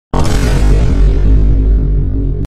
Vine Boom Slowed Sound Button: Unblocked Meme Soundboard
Play the iconic Vine Boom Slowed sound button for your meme soundboard!